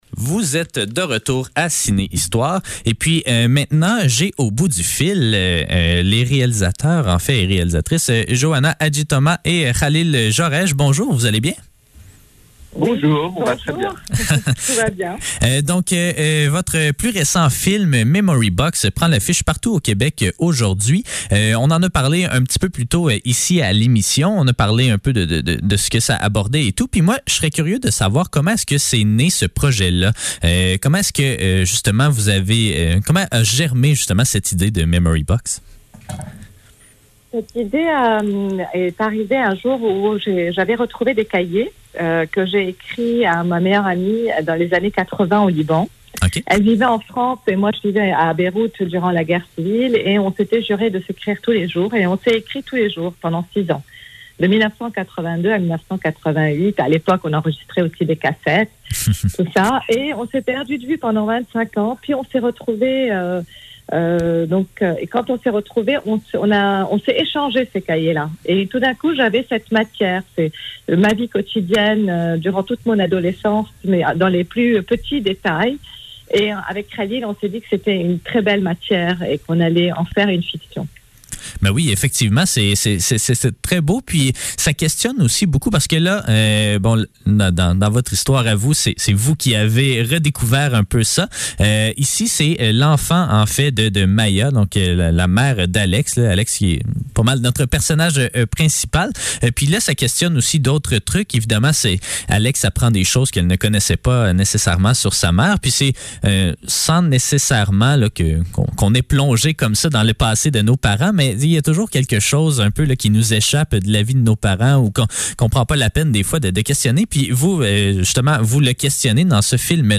Ciné-histoire - Entrevue avec Joana Hadjithomas et Khalil Joreige - 25 mars 2022